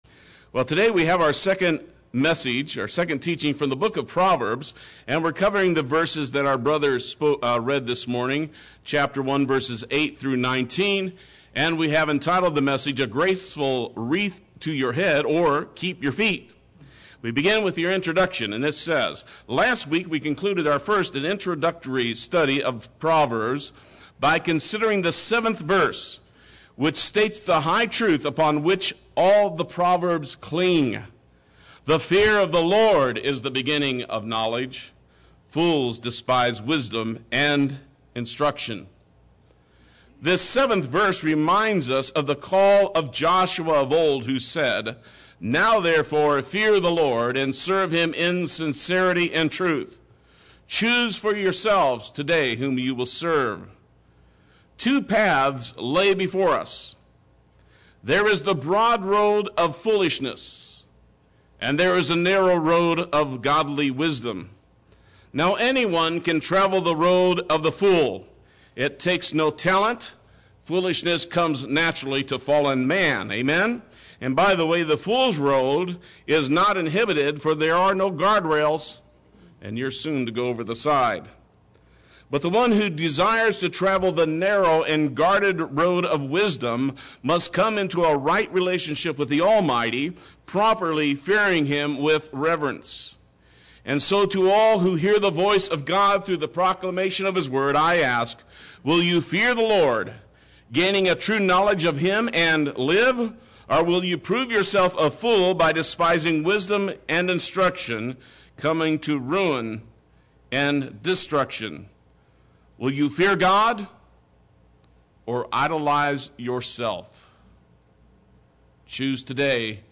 Play Sermon Get HCF Teaching Automatically.
A Graceful Wreath to Your Head” or “Keep Your Feet” Sunday Worship